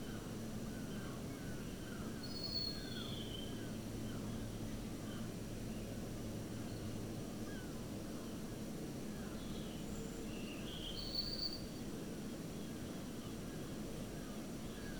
▶ Sound of MEMS with bit depth mismatch, shielded and short cables, stereo
4_mems_stereo_16bit-instead-of-32_short-cable.wav